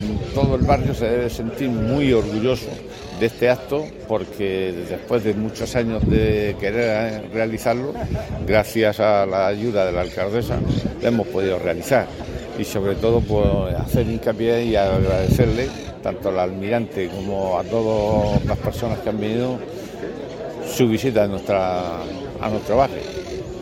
Audio: Declaraciones de la alcaldesa, Noelia Arroyo, izado de Bandera en Santa Luc�a (MP3 - 1,30 MB)